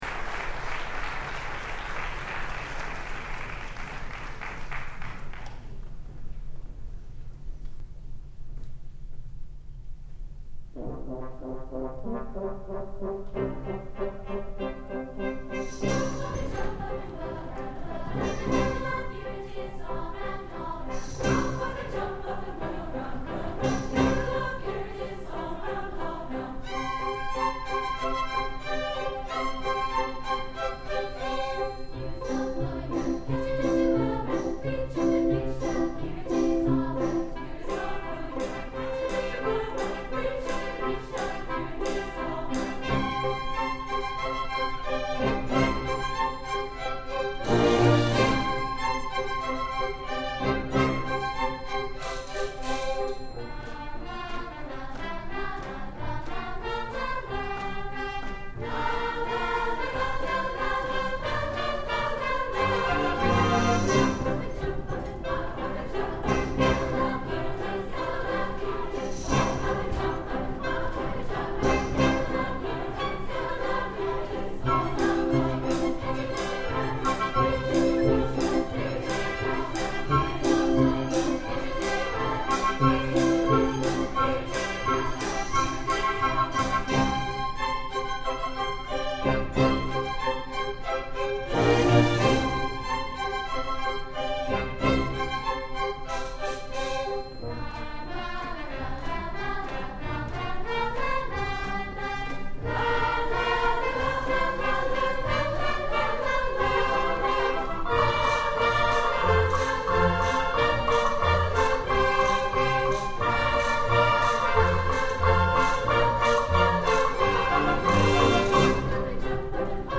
for SATB Chorus, Children's Chorus, and Orchestra (2002)